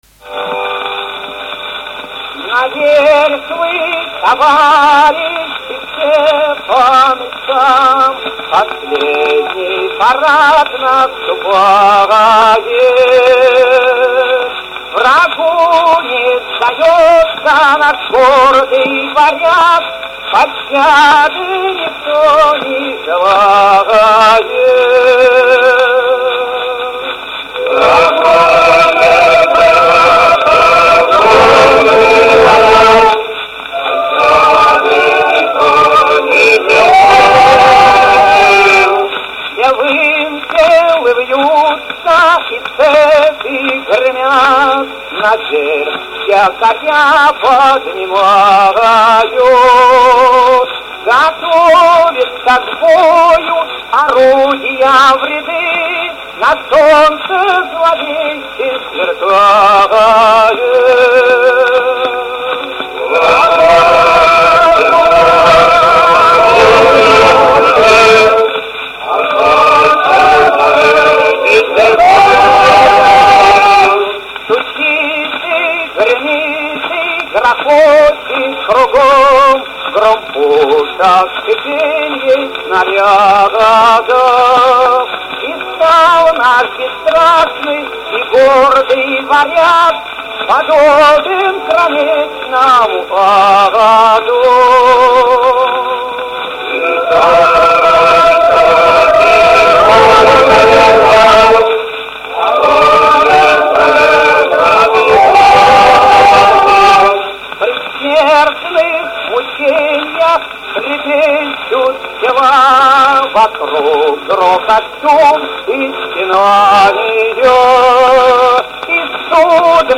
01-hor-pavlova---pamyati-varyaga.mp3